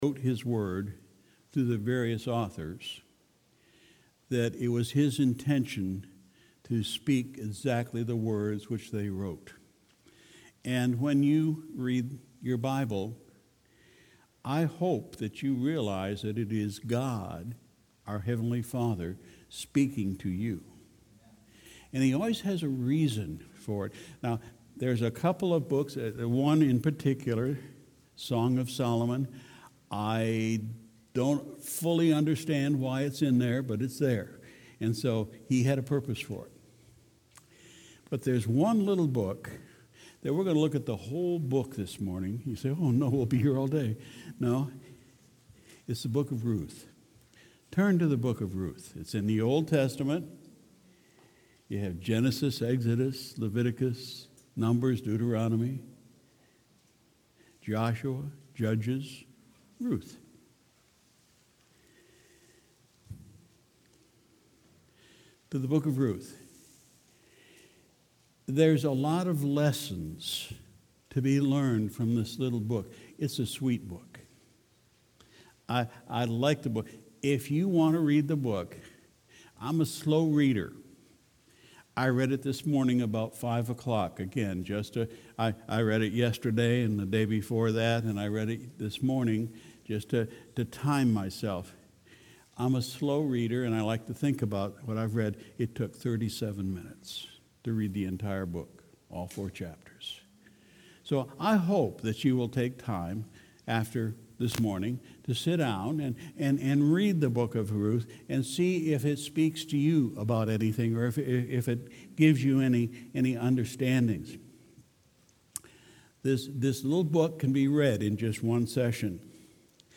Sunday, October 20, 2019 – Morning Service – Ruth